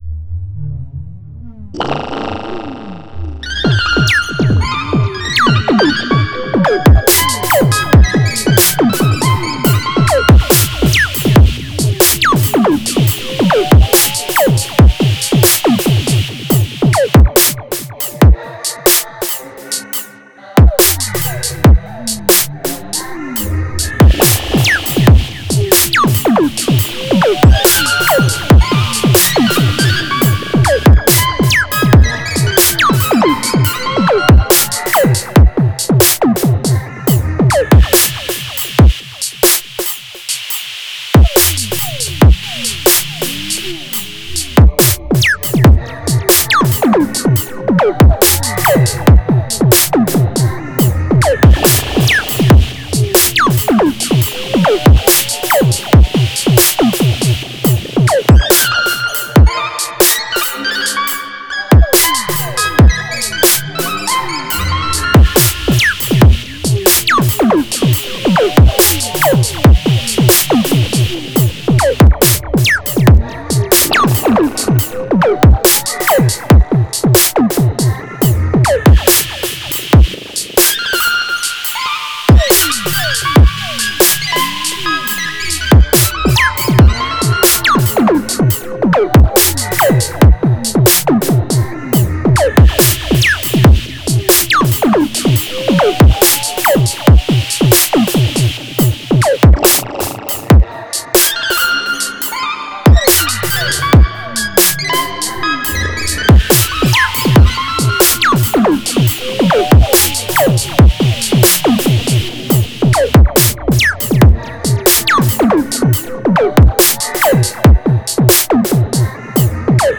Genre: IDM, Eexperimental.